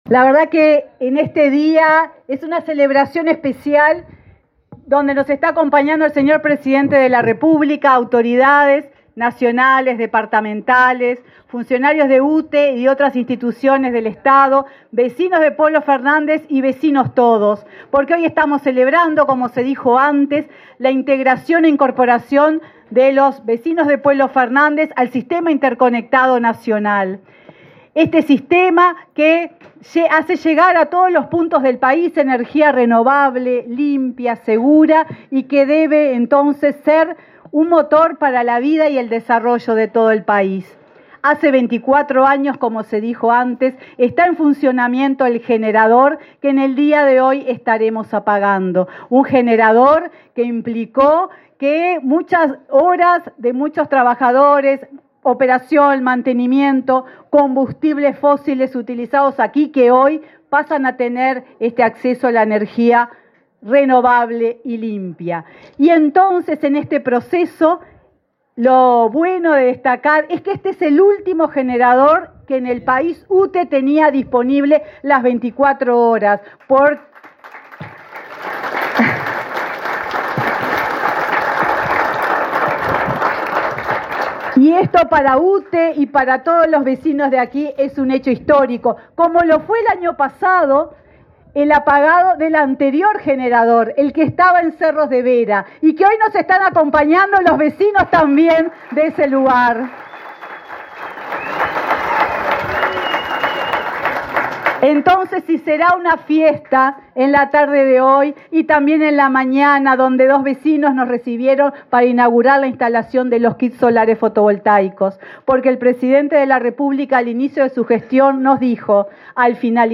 Palabras de la presidenta de UTE, Silvia Emaldi
En el acto, la presidenta de UTE, Silvia Emaldi, realizó declaraciones.